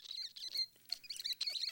content/hifi-public/sounds/Animals/rat2.wav at main
rat2.wav